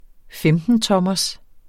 Udtale [ ˈfεmdənˌtʌmʌs ]